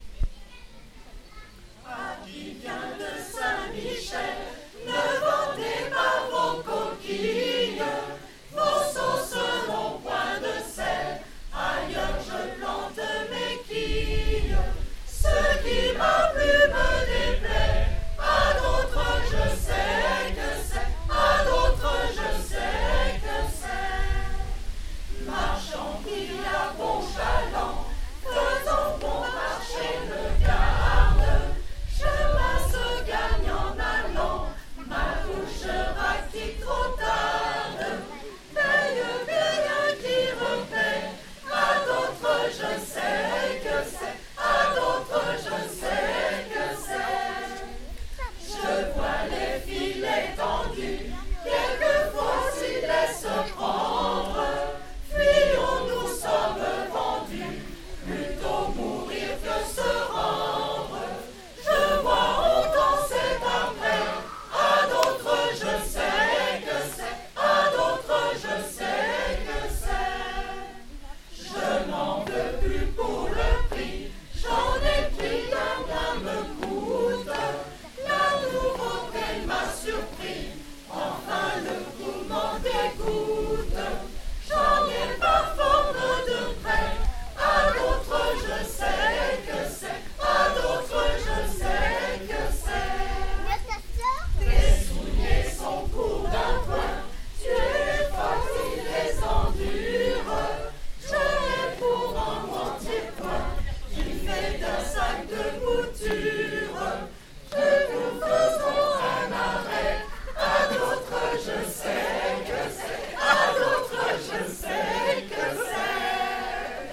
Concert juin 2016 – Ensemble Vocal Romantica
Concert juin 2016
Concert de fin d’année à la ferme du Couvent